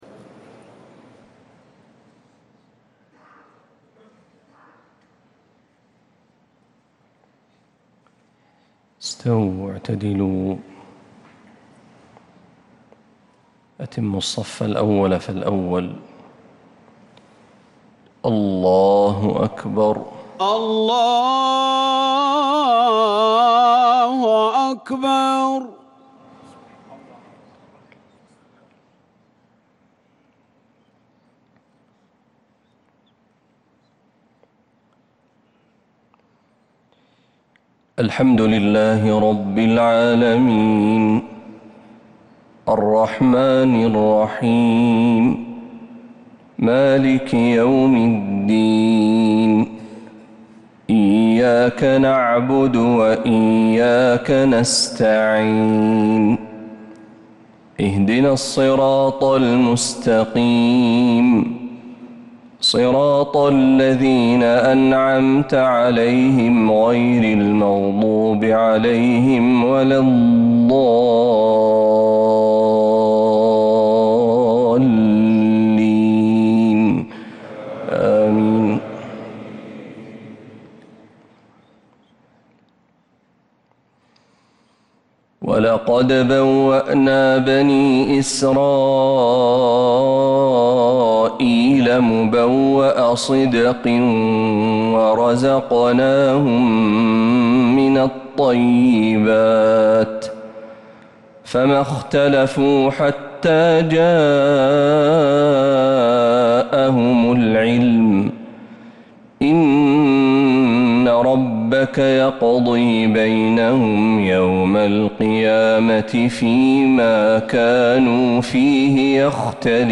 فجر الإثنين 16 ربيع الأول 1447هـ | خواتيم سورة يونس 93-109 | Fajr prayer from Surat Yunus 8-9-2025 > 1447 🕌 > الفروض - تلاوات الحرمين